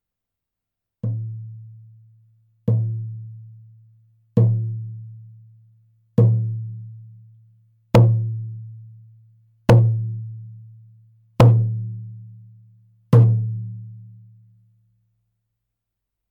フレームドラム　ネイティブアメリカン（インディアン）スタイル
素材：牛革・天然木
パキスタン製フレームドラム 音